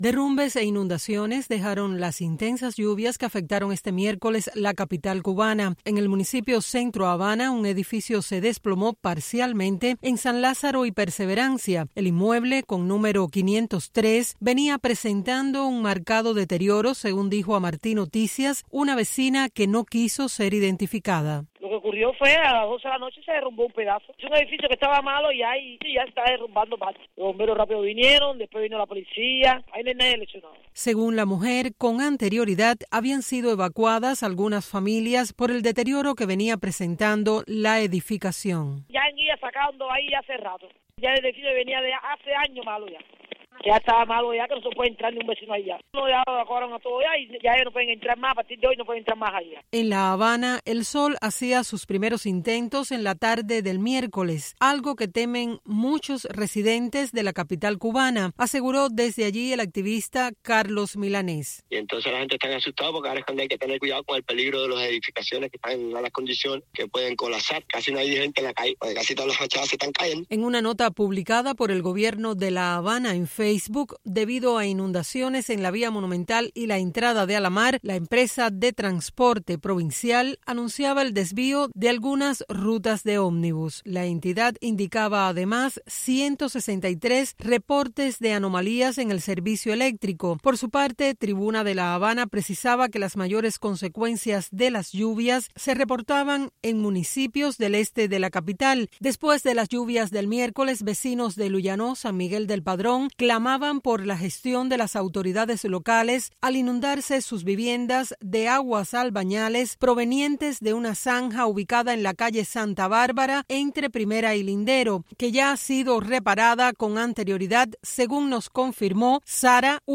Según la mujer, con anterioridad habían sido evacuadas algunas familias por el deterioro que venía presentando la edificación.